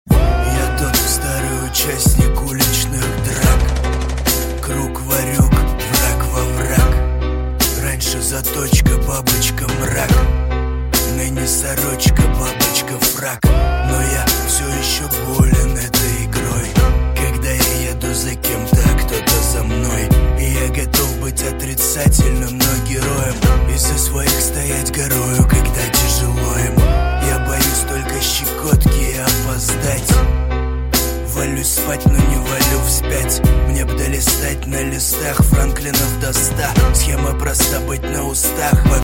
• Качество: 128, Stereo
громкие
русский рэп